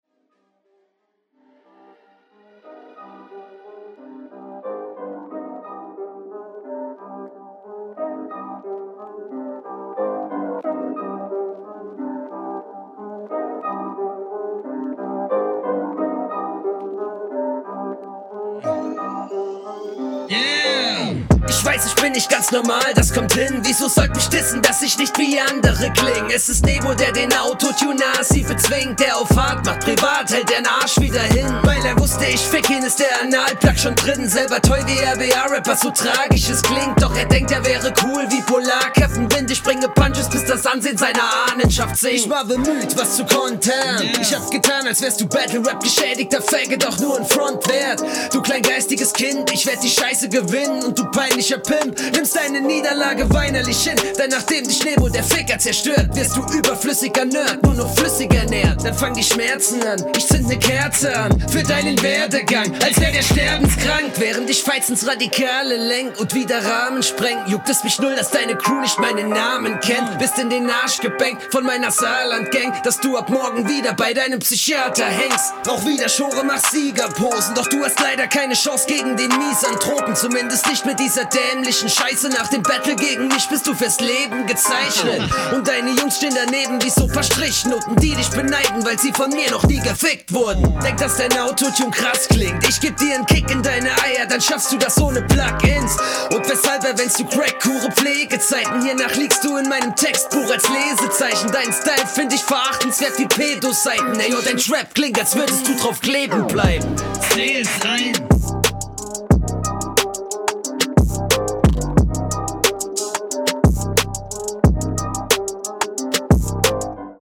Damn geiler Stimmeinsatz - gefällt mir, hat was extravagantes.